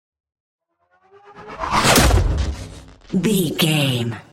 Chopper whoosh to hit engine
Sound Effects
intense
tension
woosh to hit